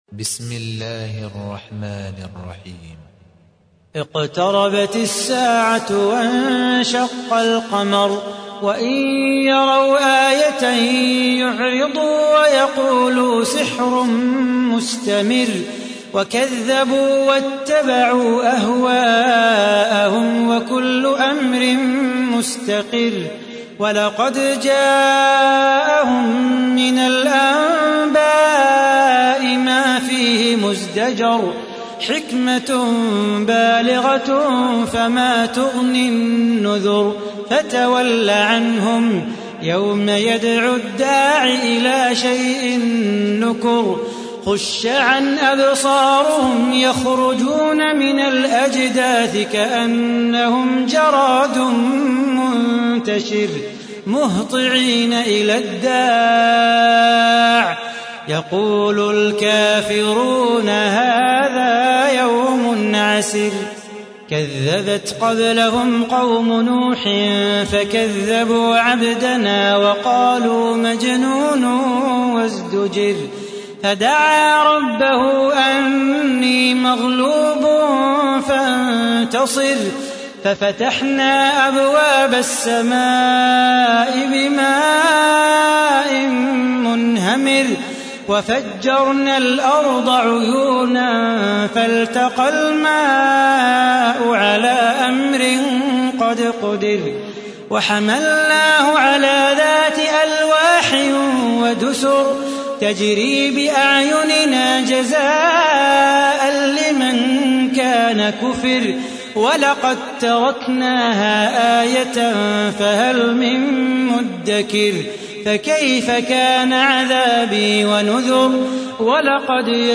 تحميل : 54. سورة القمر / القارئ صلاح بو خاطر / القرآن الكريم / موقع يا حسين